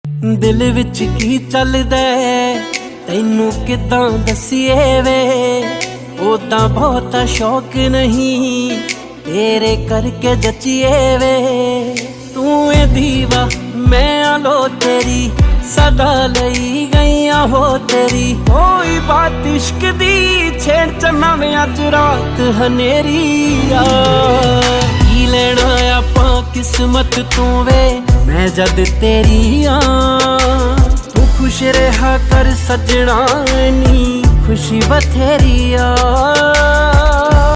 punjabi song ringtone download mp3